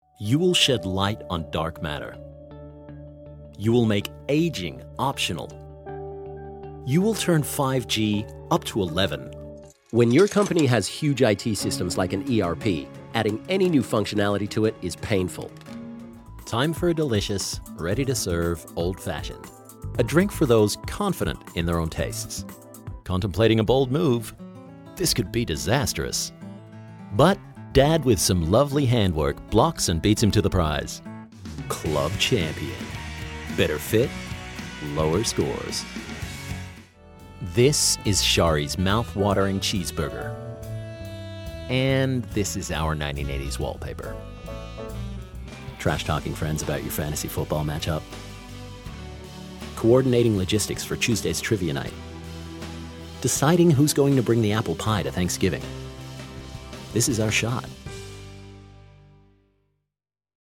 Canadian/Australian voice actor.
US Commercial Demo
Australian, Standard US, RP